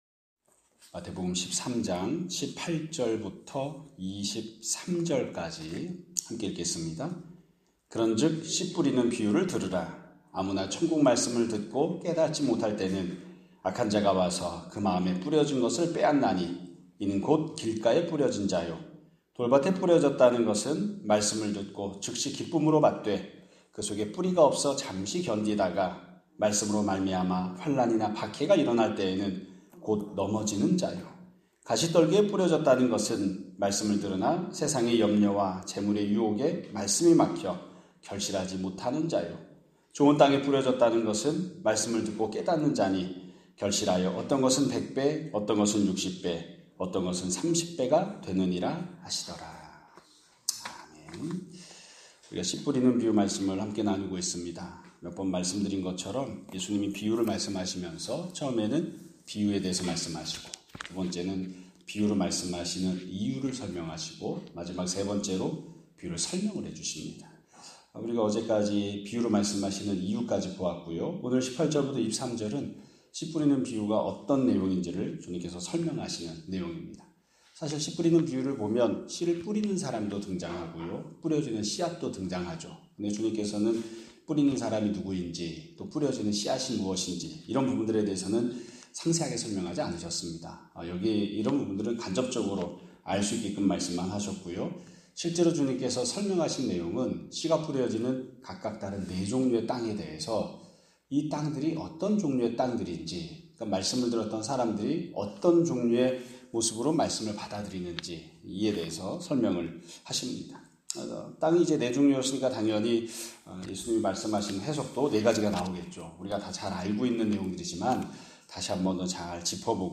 2025년 9월 26일 (금요일) <아침예배> 설교입니다.